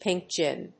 アクセントpínk gín